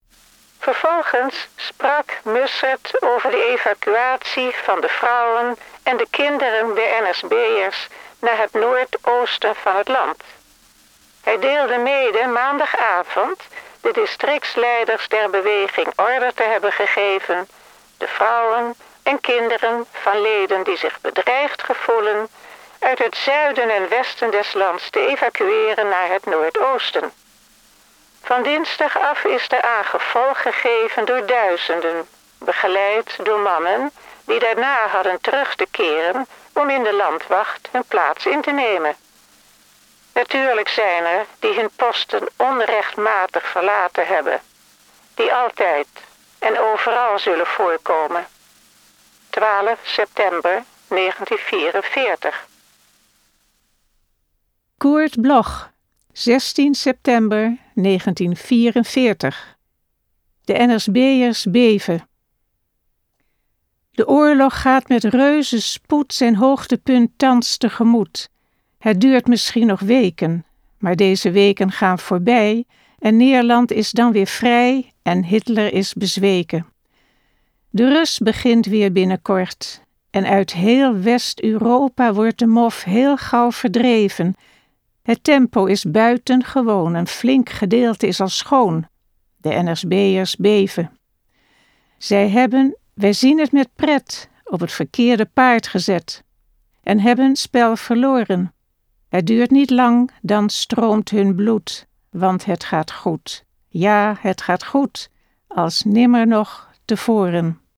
Recording: Karakter sound, Amsterdam